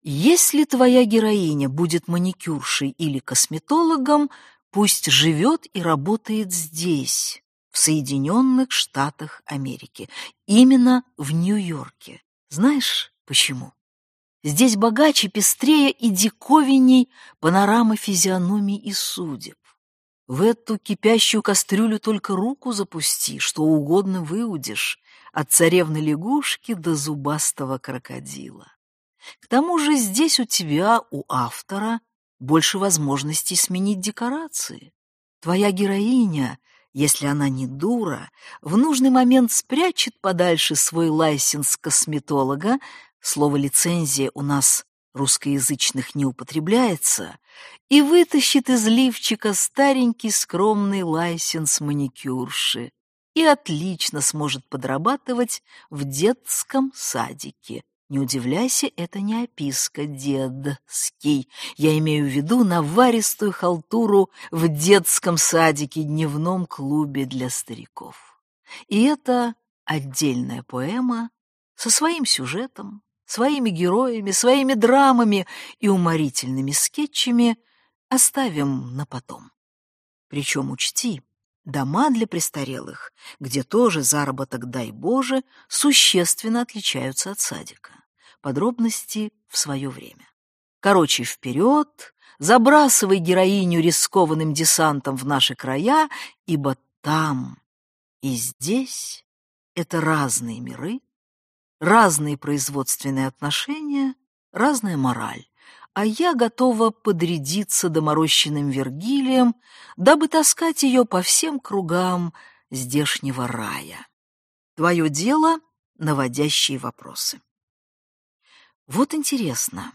Аудиокнига Бабий ветер - купить, скачать и слушать онлайн | КнигоПоиск